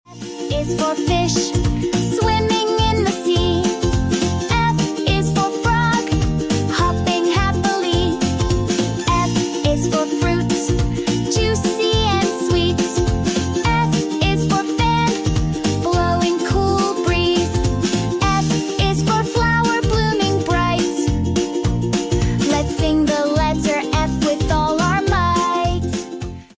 colorful and joyful ABC song